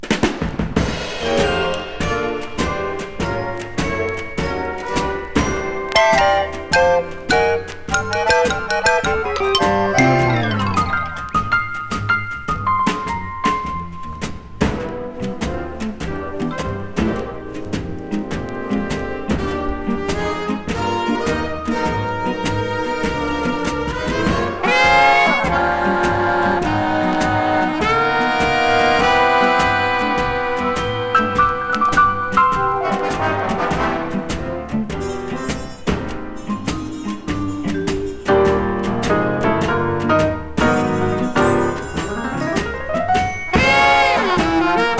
Jazz, Big Band, Space-Age　USA　12inchレコード　33rpm　Stereo